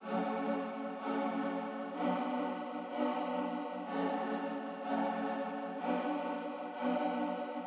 描述：用果味圈做的合唱
Tag: 125 bpm Trap Loops Choir Loops 1.29 MB wav Key : Unknown